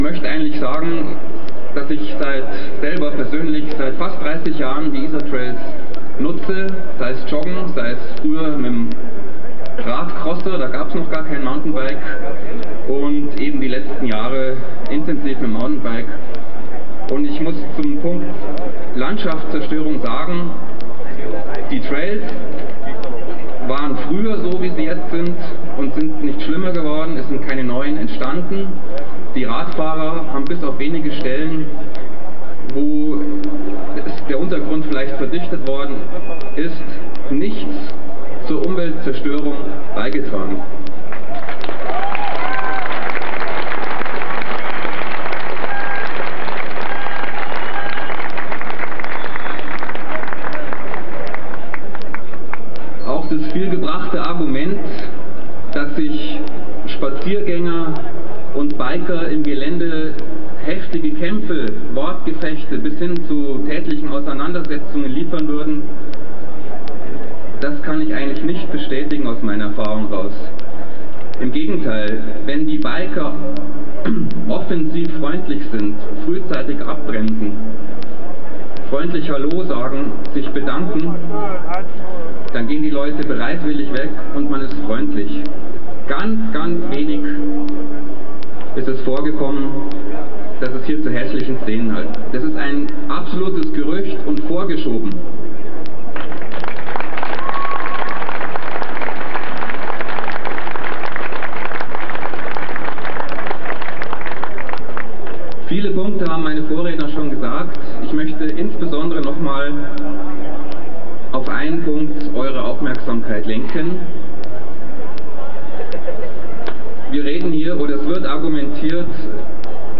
Tonbeitrag Fuji-Werbespot, 02.01.14